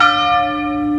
A chaque déplacement de la cloche correspondra un son de cloche.
dong11.mp3